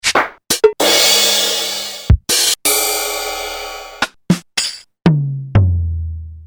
Digital Drum Machine (1983)
Sounds are: bass drum, snare, rimshot, tom (x2), crash cymbal + ride, open/ closed hihat, handclaps, tambourine, cabasa and cowbell.